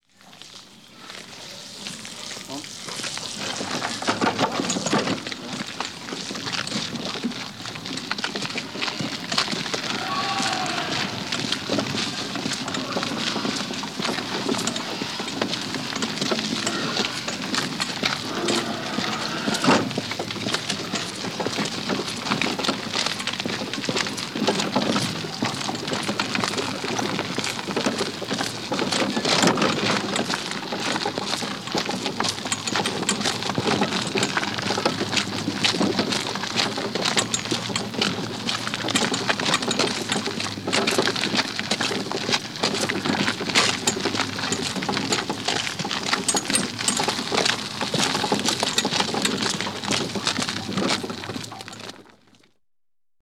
Тележка с фруктами катится по центру рынка